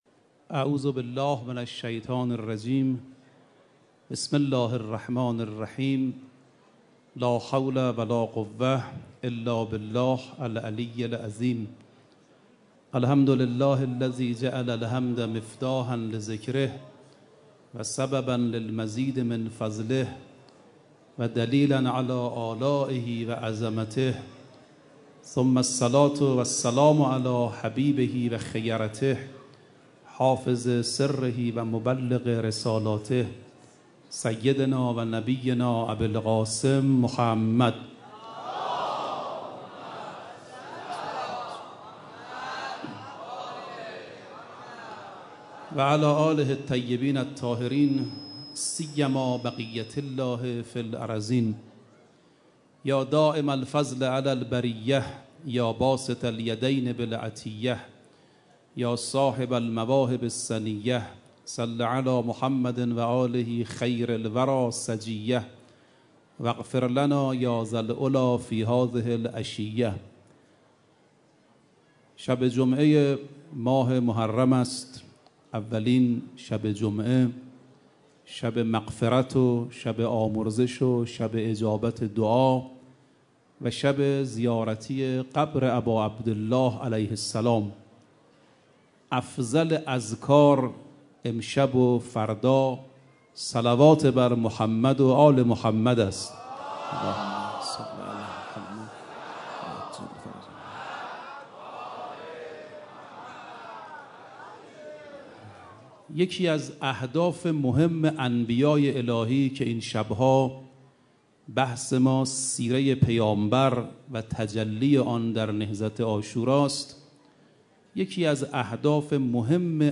شب پنجم محرم 95_سخنراني